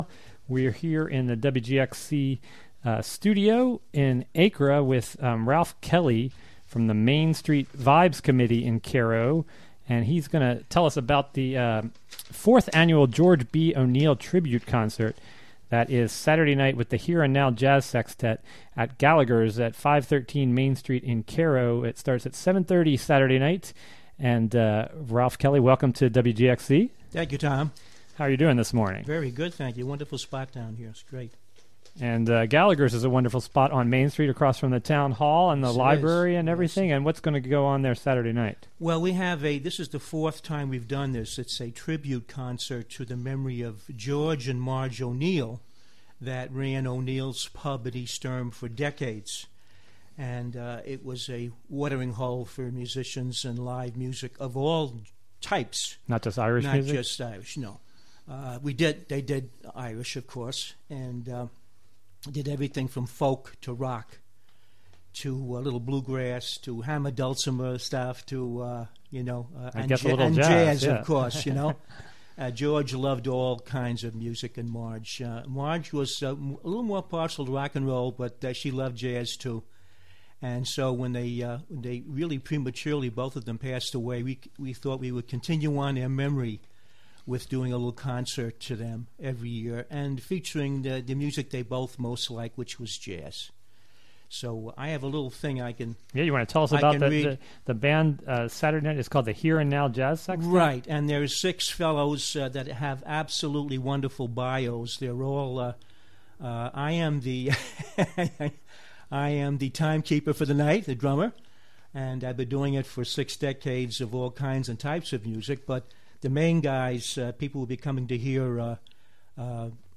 10am The WGXC Morning Show is a radio magazine show fea...